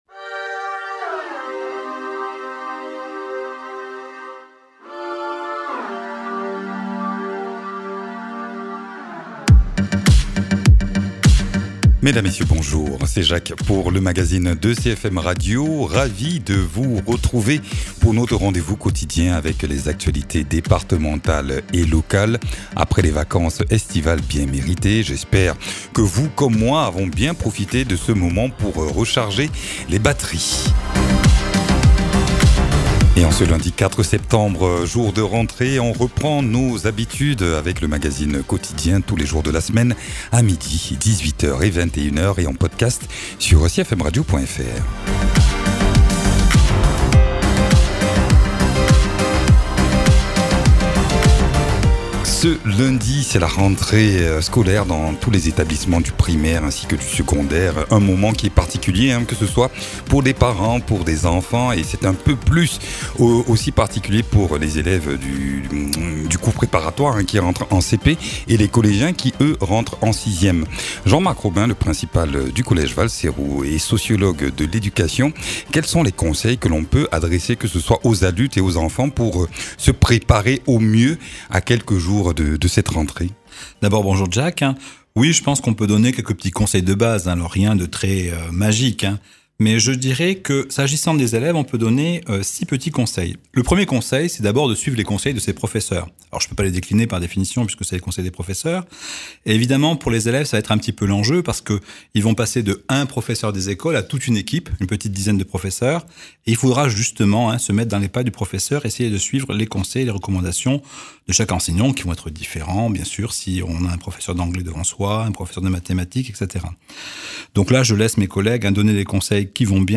Dans ce magazine de rentrée avec notre invité nous abordons quelques conseils pratiques pour aborder au mieux cette rentrée scolaire que l’on soit élèves ou parents. Aussi, il est question de la circulaire de rentrée qui fixe la feuille de route de la pédagogie et qu’ont reçu tous les chefs d’établissements il y a quelques semaines déjà.